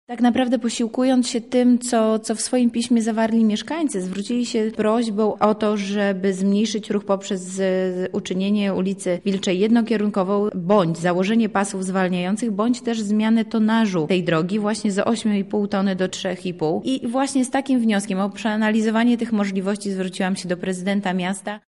O tym, czego dokładnie dotyczy dokument, mówi Beata Stepaniuk-Kuśmierzak, radna miasta Lublin.